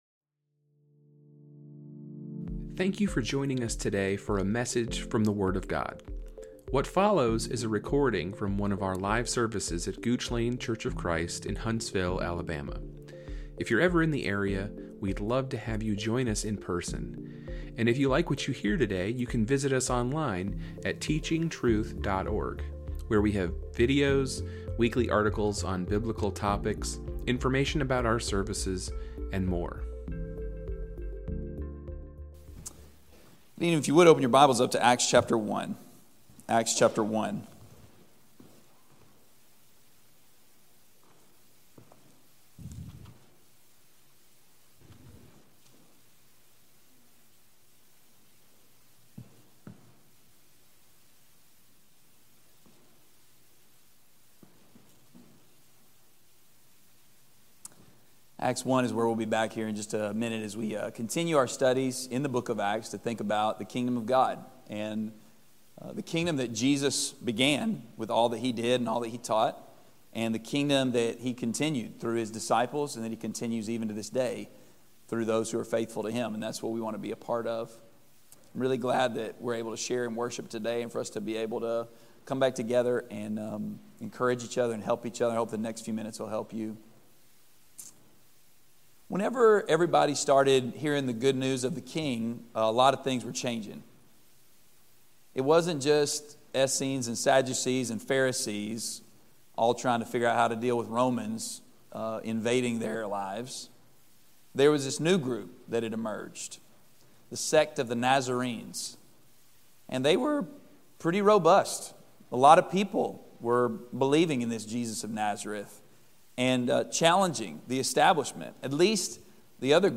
from our gospel meeting in June 2023.